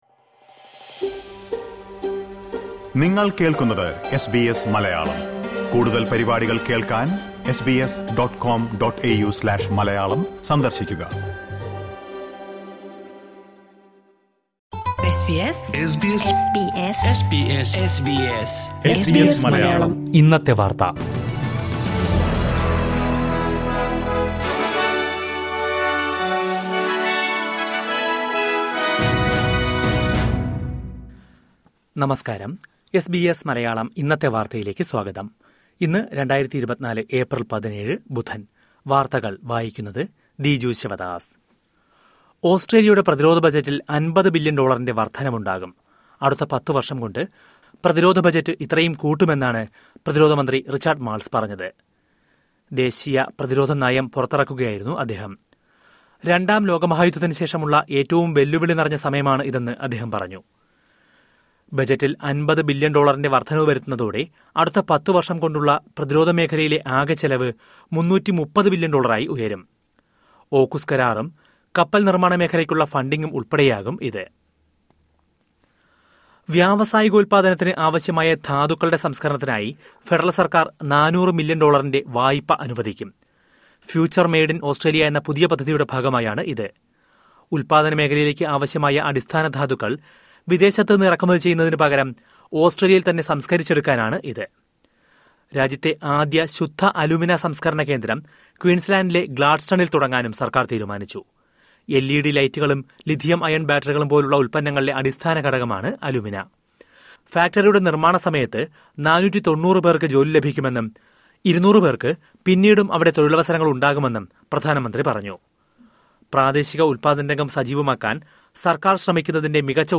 2024 എപ്രില്‍ 17ലെ ഓസ്‌ട്രേലിയയിലെ ഏറ്റവും പ്രധാന വാര്‍ത്തകള്‍ കേള്‍ക്കാം...